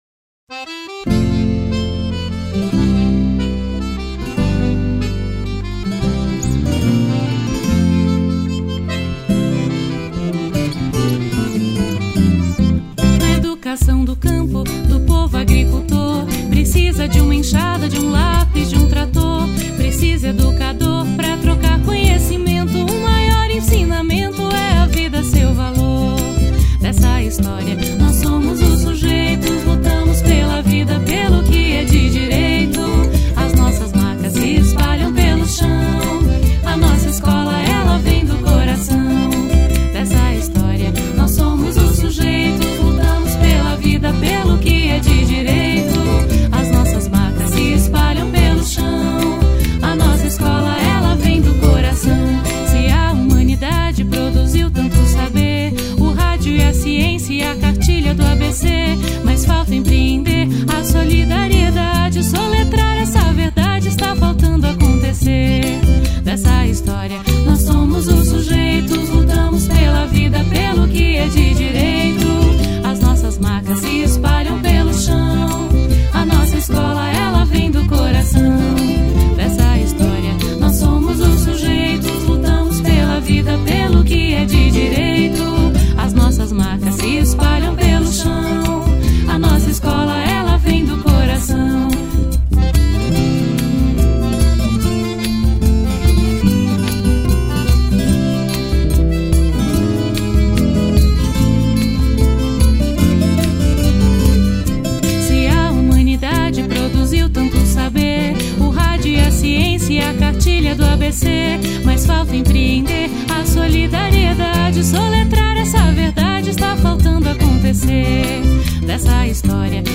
Ciranda